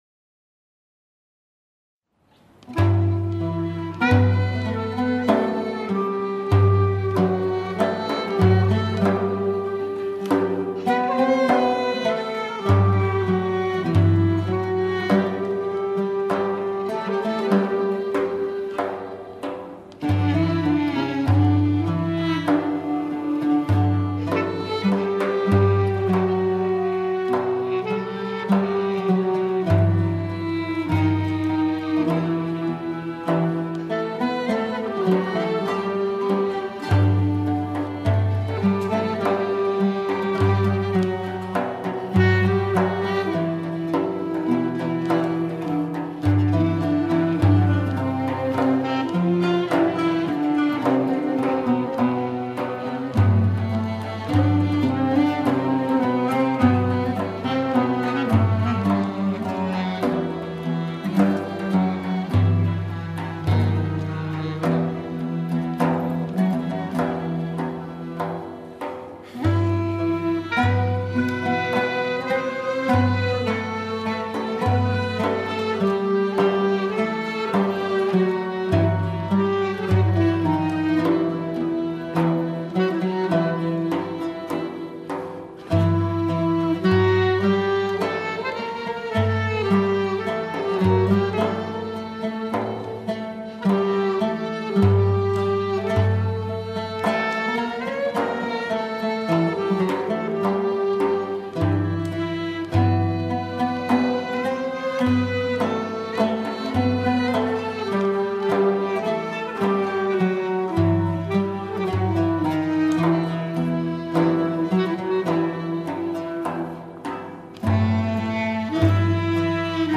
Twee door het orkest uitgevoerde stukken zijn hier ook digitaal te beluisteren.
Openingsmelodie in Ferahfeza, het begin van een langere religieuze suite, is gecomponeerd door componist en derwisj Hamamizade İsmail Dede Efendi (kortweg: Dede Efendi), die eind achttiende, begin negentiende eeuw leefde.